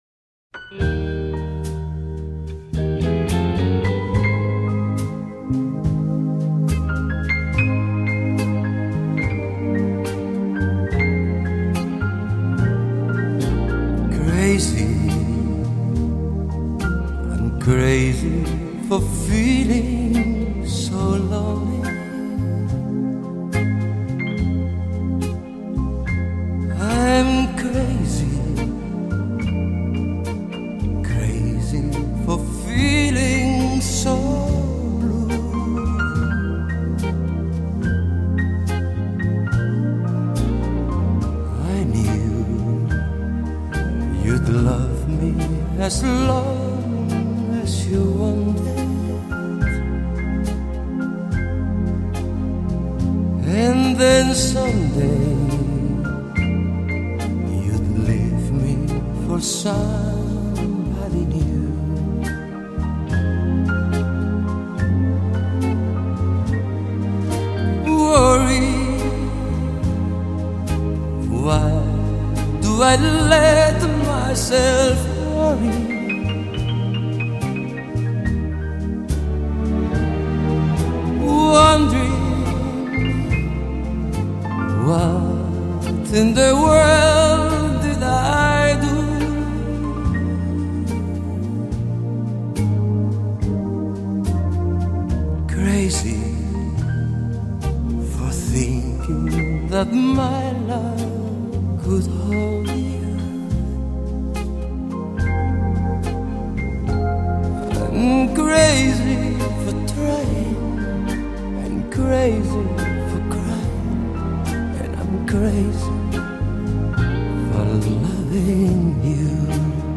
充满磁性的歌声一下子就深深地吸引了我的耳朵